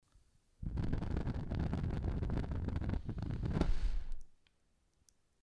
Звуки спичек, зажигалок
Медленное затухание спички